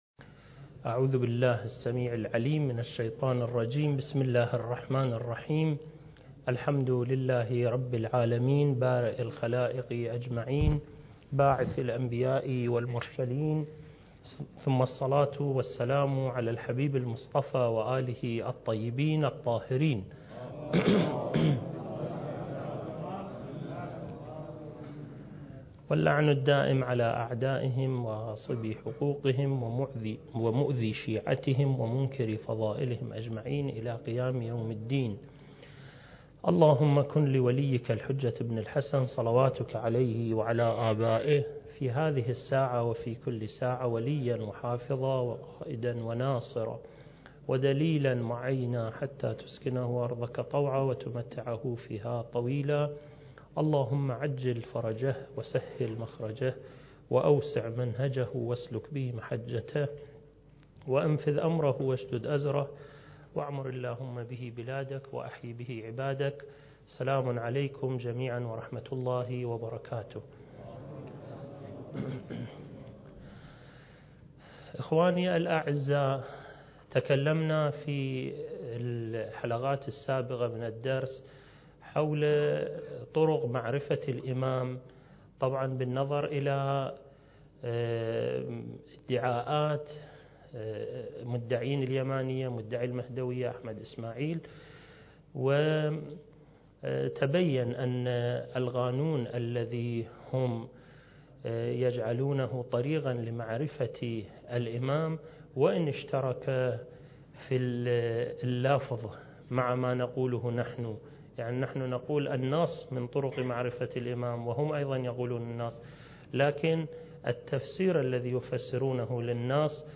المكان: مؤسسة الإمام الحسن المجتبى (عليه السلام) - النجف الأشرف دورة منهجية في القضايا المهدوية (رد على أدعياء المهدوية) (9) التاريخ: 1443 للهجرة